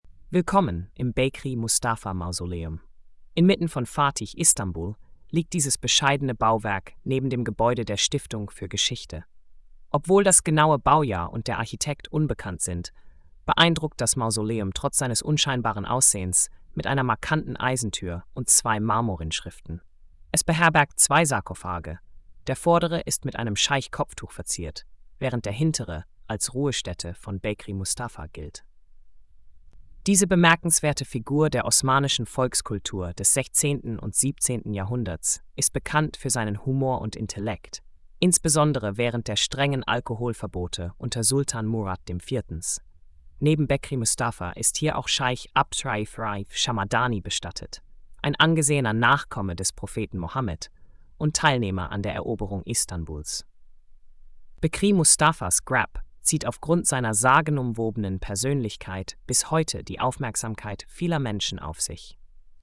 Audio Erzählung_